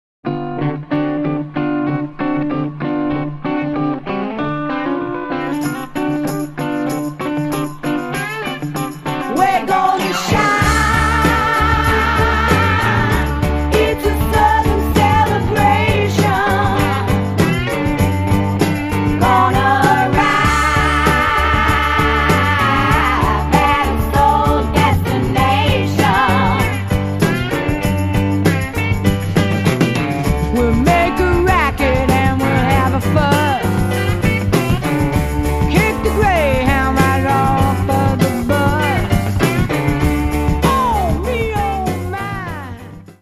SWAMP ROCK